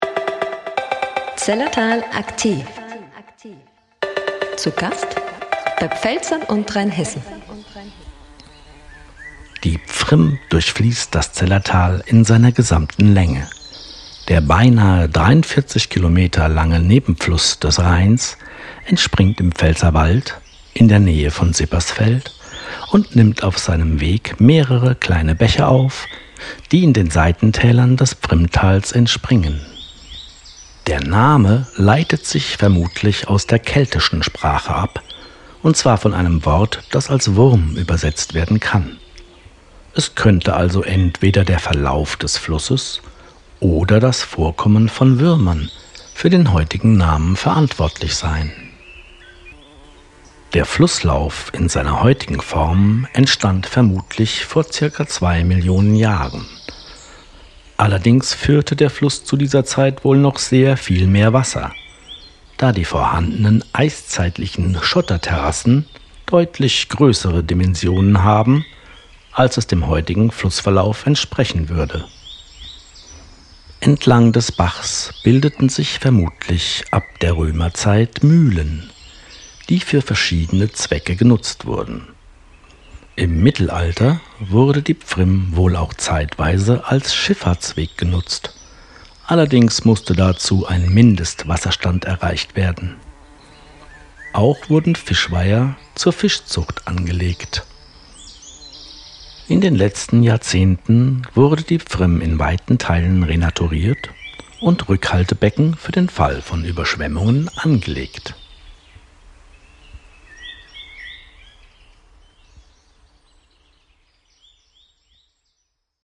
AUDIO-GUIDE DIE PFRIMM – Willkommen im Zellertal